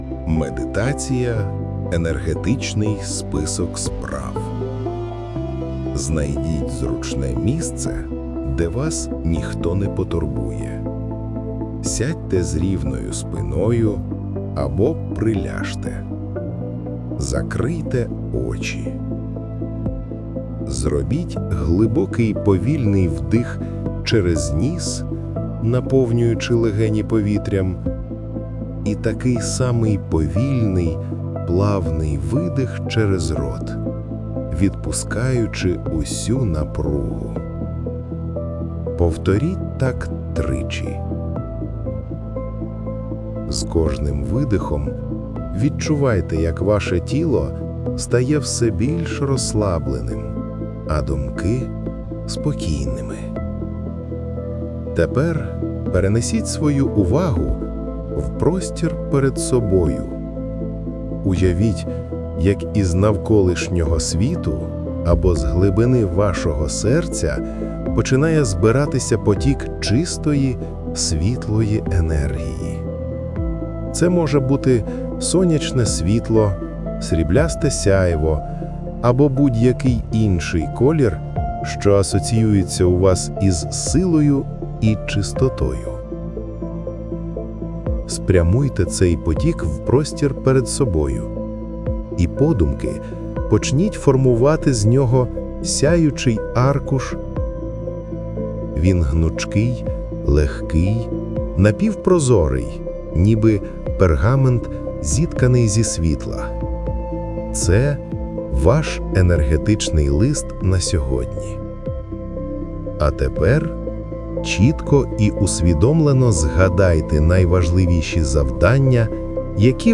Матеріали у дописі, окрім, власно написаного тексту (не процитований), згенеровано в Gogole AI Studio.
Був для мене на 75-90% звук нормальний, котрий генерував ШІ, але якось він став ніякий, а ще більше сміху було, коли виявилось, що воно лише в навушниках, якось біль-менш нормально звучить.